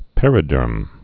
(pĕrĭ-dûrm)